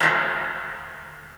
07_Snare_13_SP.wav